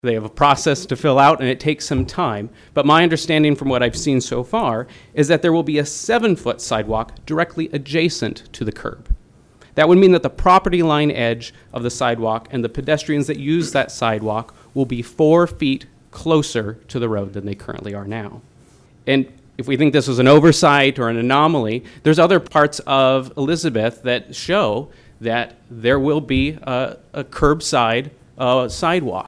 The comments came during the Pierre City Commission meeting’s public comment period.